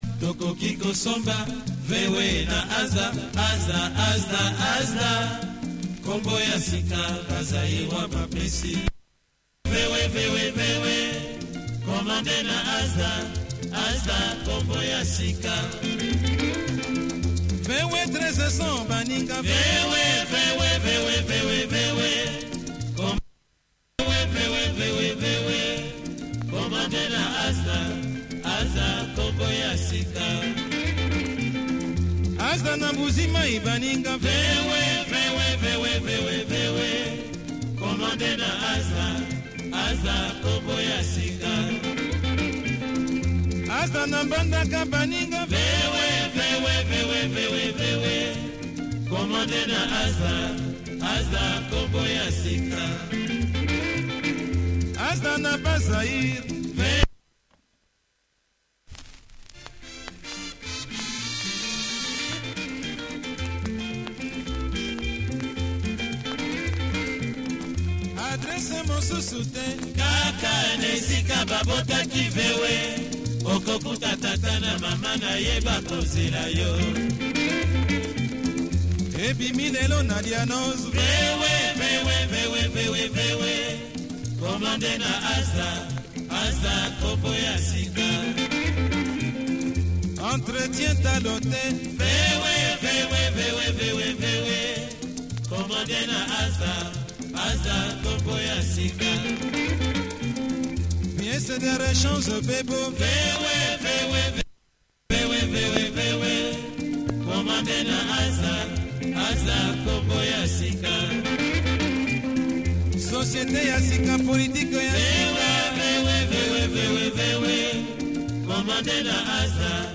great horn section!